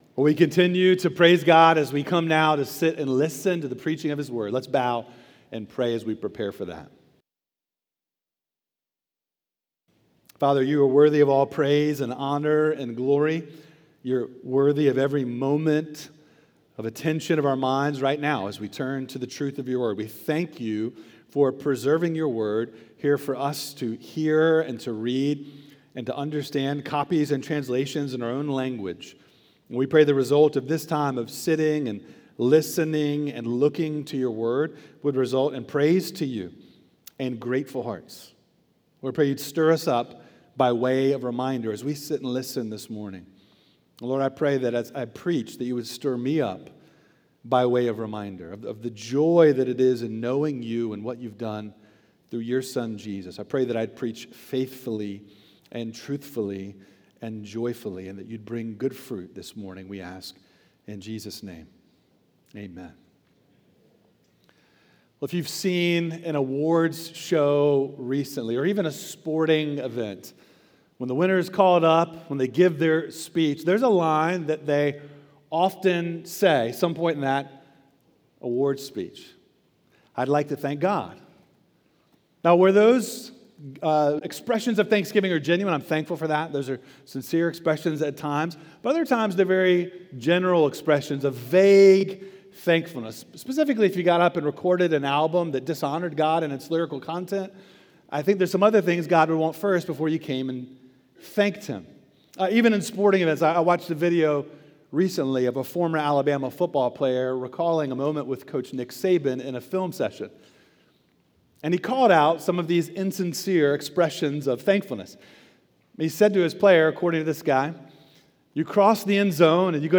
Sermons by Oakhurst Baptist Church Sermons